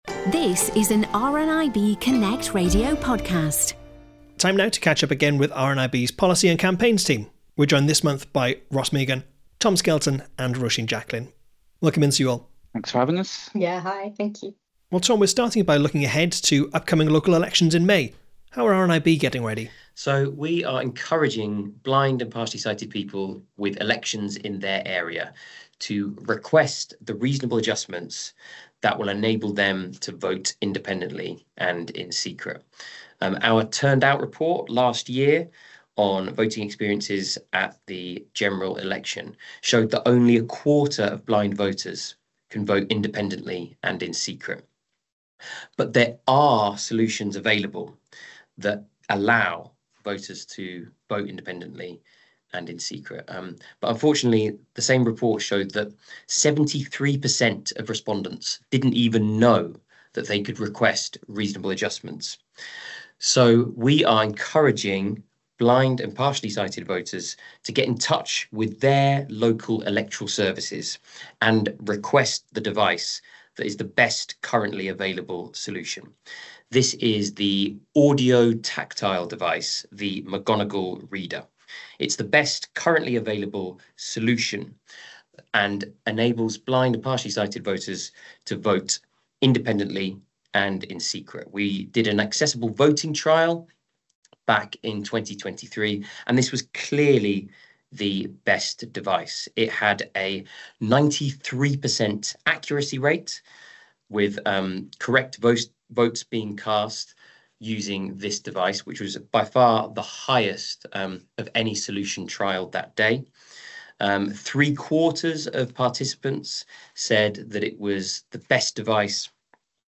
Each month, RNIB Connect Radio speak to some of the RNIB Campaigns Team to discuss some of the big projects they’re working on.